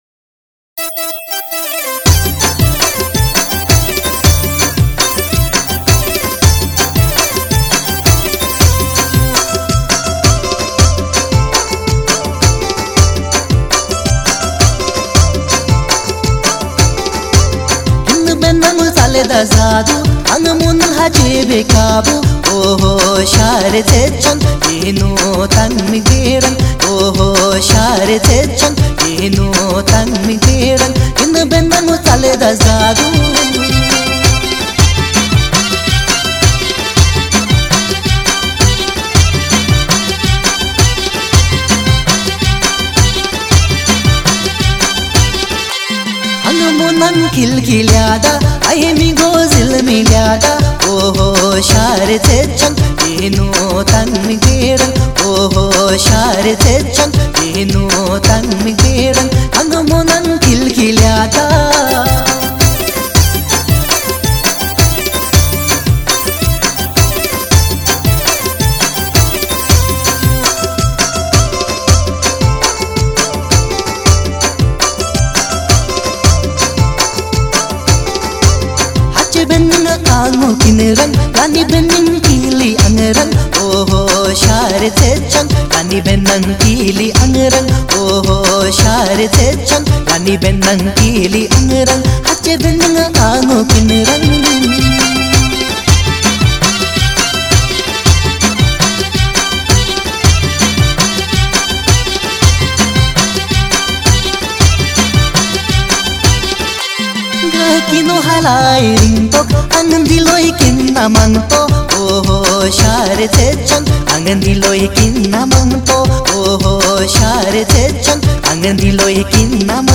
Kinnauri Dancing Song's